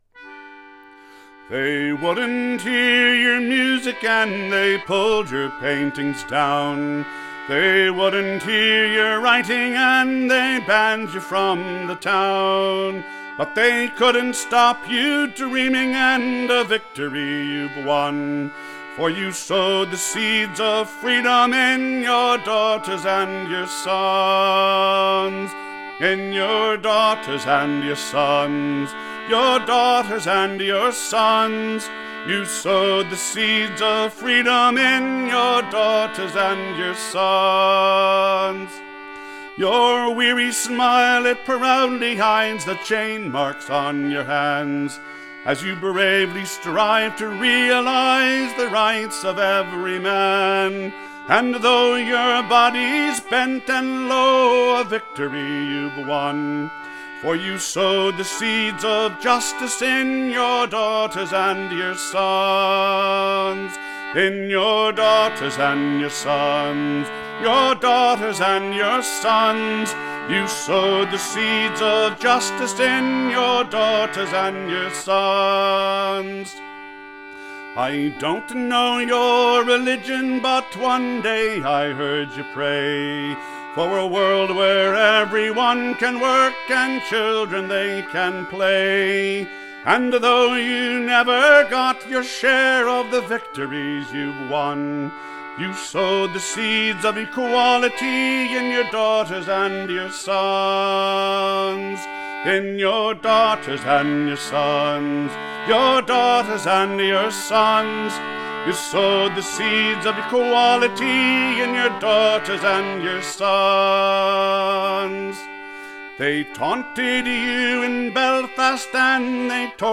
protest song